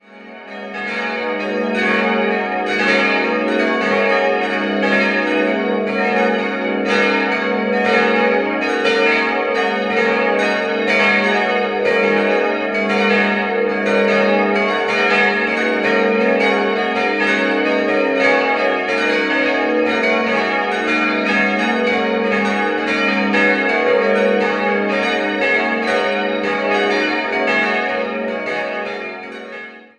Wesentlich jünger hingegen ist die Christuskirche mit ihrem freistehenden Glockenturm. Sie wurde im Jahr 1959 errichtet. 5-stimmiges Geläute: gis'-ais'-cis''-dis''-fis'' Alle Glocken stammen aus der Gießerei Bachert und wurden 1959 gegossen.